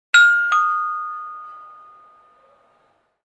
Classic Doorbell Ding Dong Sound Effect
Clean and realistic “ding dong” sound of a classic doorbell chime.
Genres: Sound Effects
Classic-doorbell-ding-dong-sound-effect.mp3